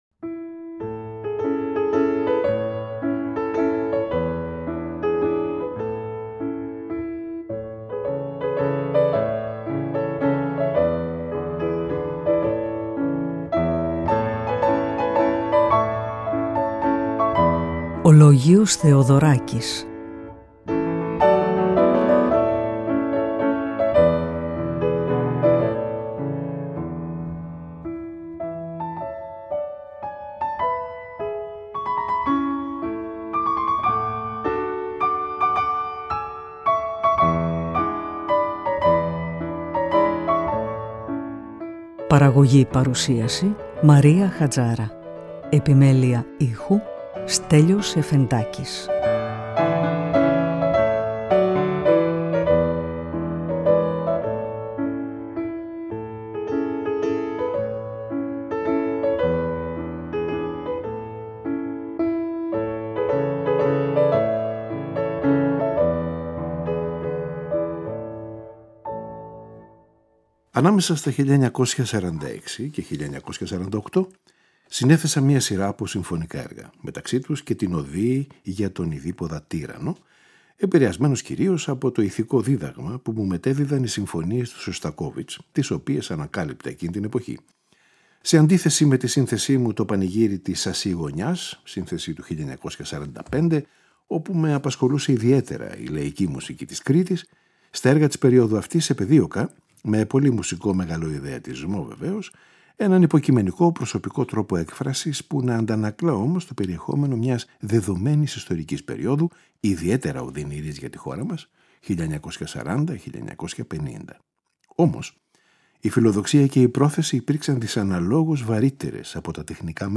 Το 1981, ο συνθέτης και φλαουτίστας Φίλιππος Τσαλαχούρης -μετά από παραγγελία του Νέου Ελληνικού Κουαρτέτου- μεταγράφει τον Οιδίποδα για κουαρτέτο εγχόρδων και το έργο ονομάζεται Κουαρτέτο Αρ.3, Epoca Nocturna (Νύκτια Εποχή).